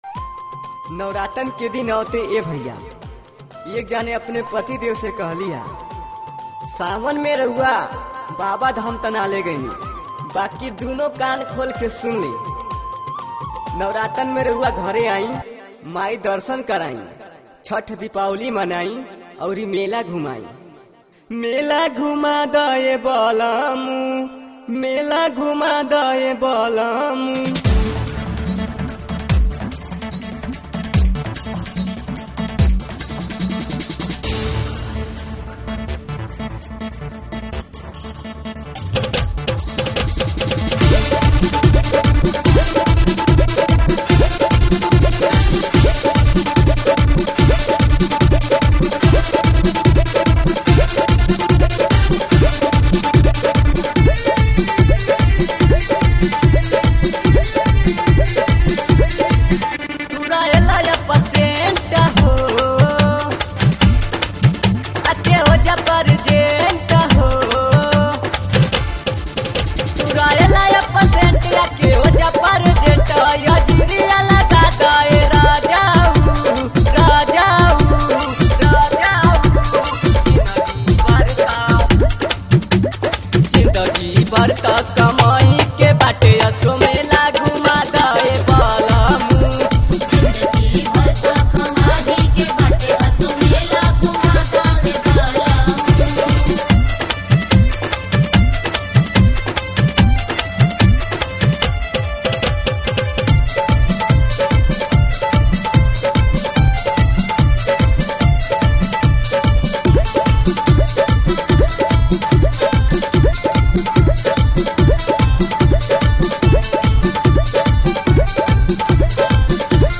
Devotional (Bhajan)